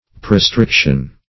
Search Result for " prestriction" : The Collaborative International Dictionary of English v.0.48: Prestriction \Pre*stric"tion\, n. [L. praestrictio a binding fast, fr. praestringere.